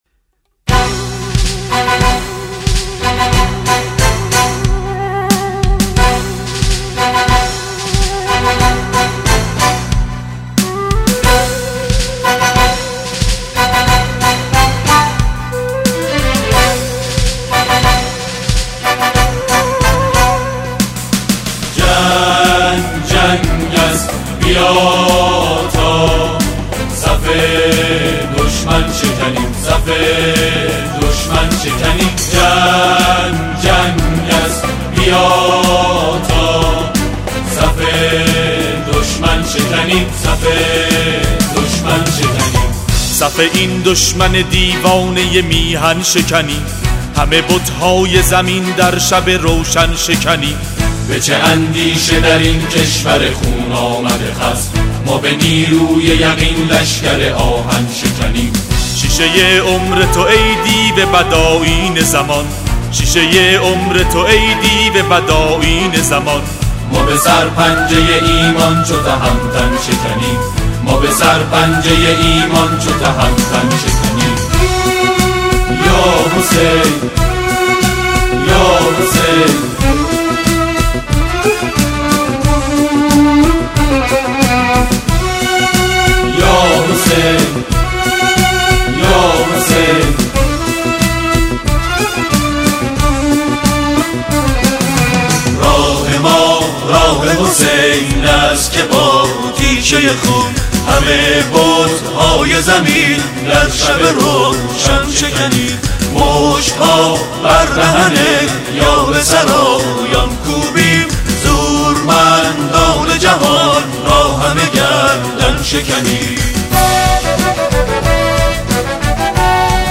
صوت سرود